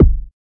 edm-kick-45.wav